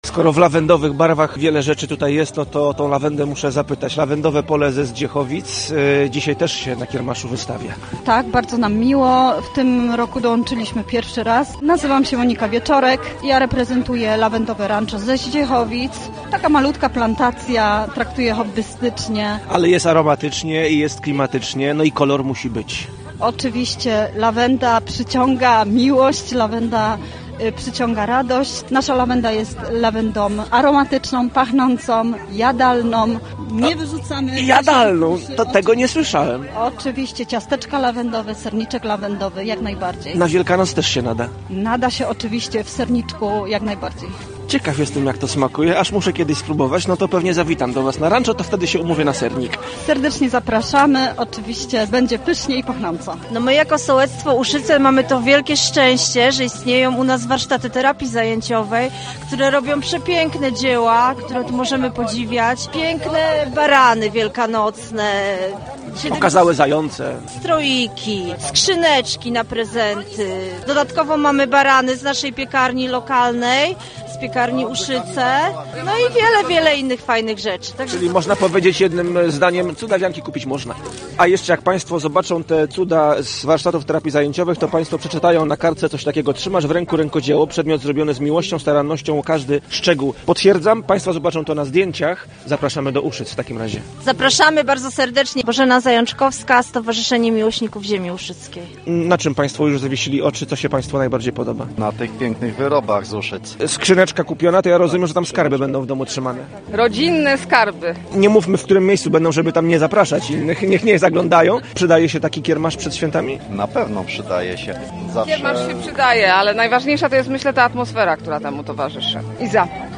W Gorzowie Śląskim w mienioną niedzielę odbył się tradycyjny kiermasz wielkanocny. Swoje wyroby kulinarne i rękodzielnicze oferowały sołectwa Dębina, Zdziechowice, Nowa Wieś i Uszyce.
Posłuchajcie w relacji i zobaczcie na fotografiach.